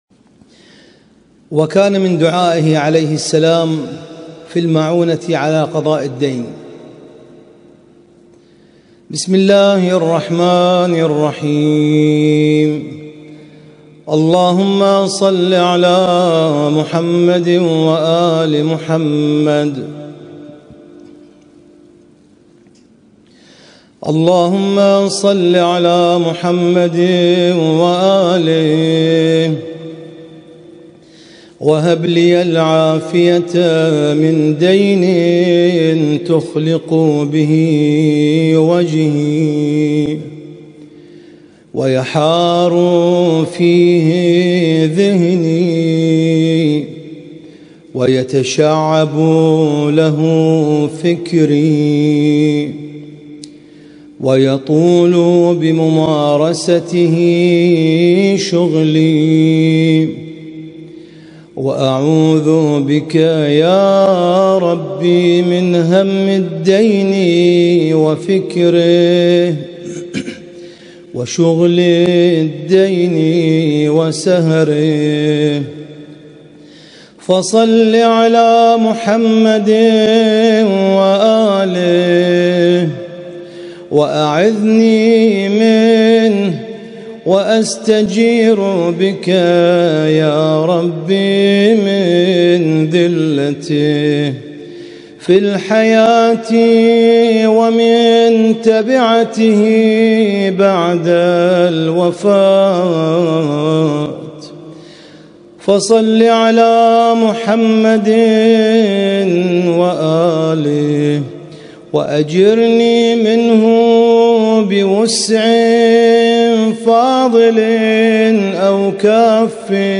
القارئ: